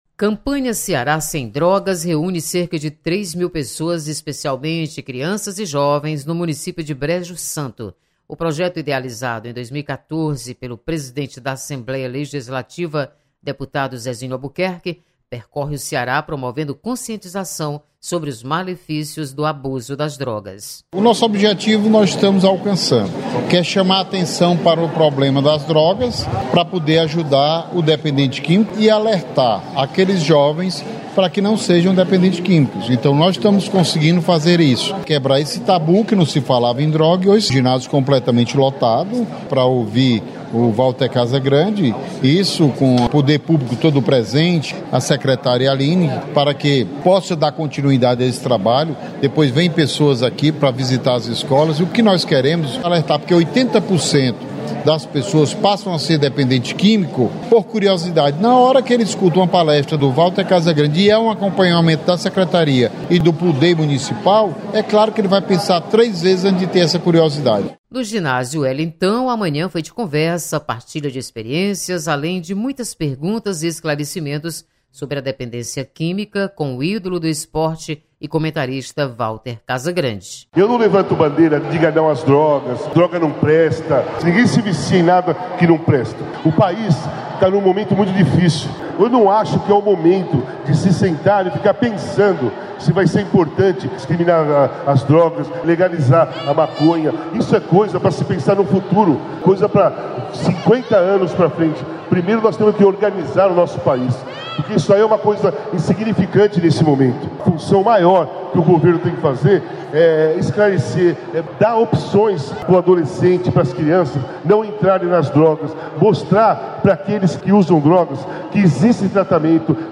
Presidente Zezinho Albuquerque comanda mais uma ação da campanha Ceará Sem Drogas. Repórter